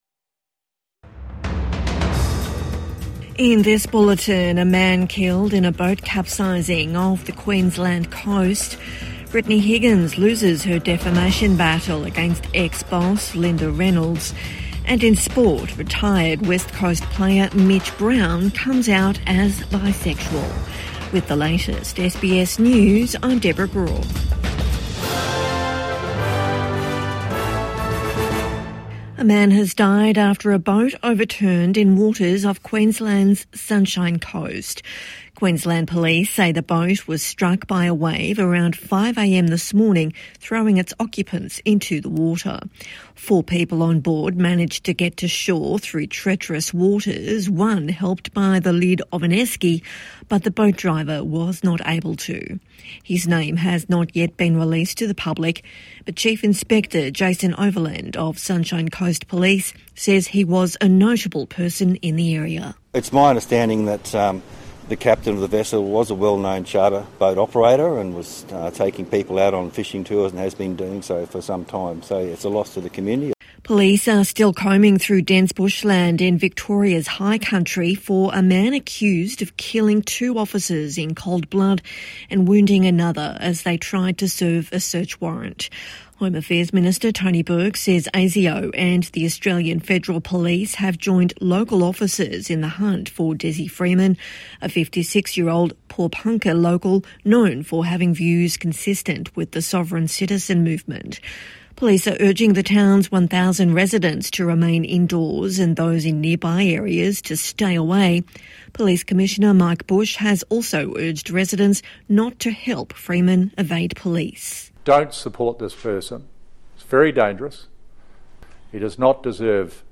Mitch Brown first AFL player to come out as bisexual | Evening News Bulletin 27 August 2025